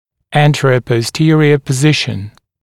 [ˌæntərəpɔs’tɪərɪə pə’zɪʃn][ˌэнтэрэпос’тиэриэ пэ’зишн]положение в сагиттальной плоскости